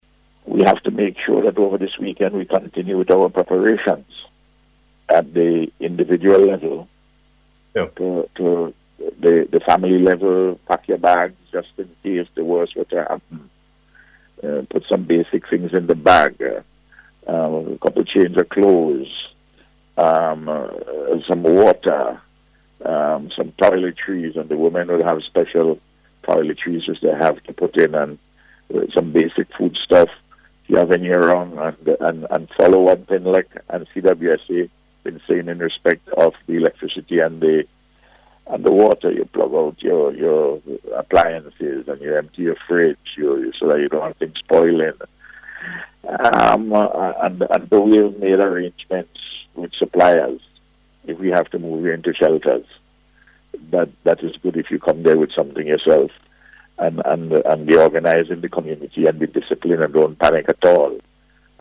Speaking on NBC Radio this morning, the Prime Minister said persons should ensure that they remain vigilant.